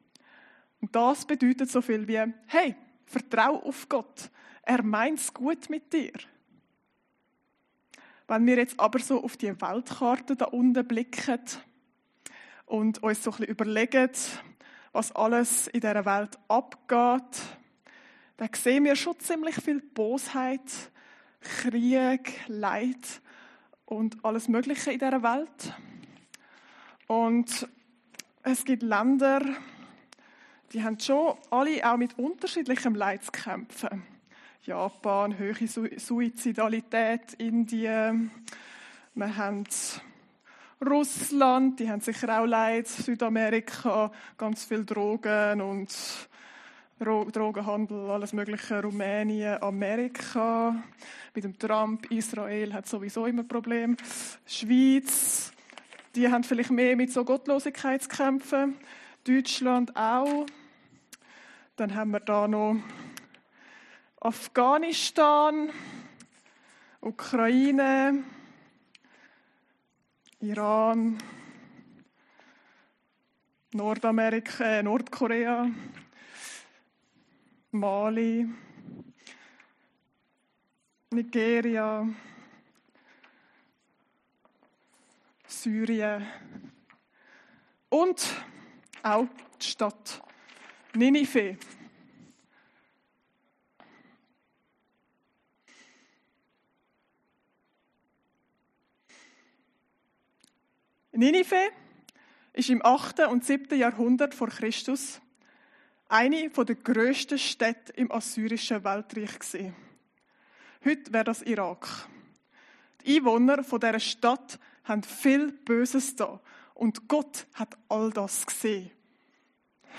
Predigten Heilsarmee Aargau Süd – Parallelen von Jona und Jesus